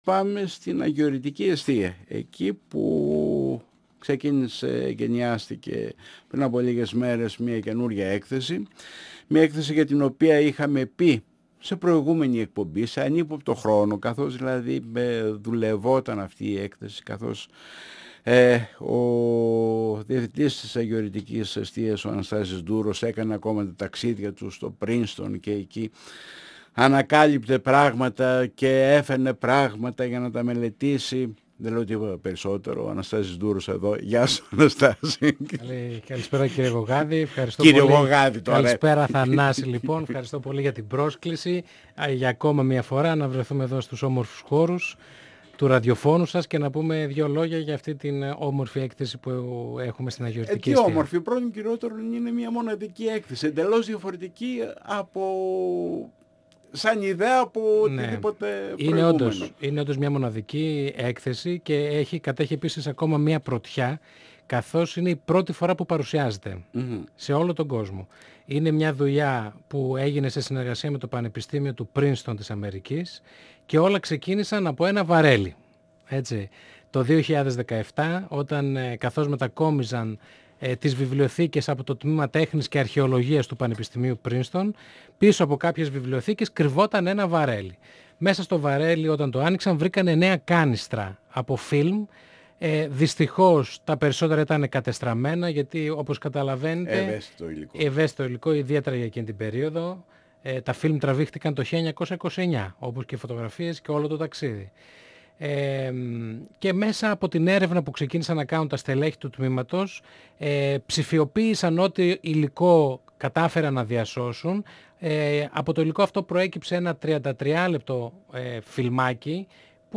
958FM Συνεντεύξεις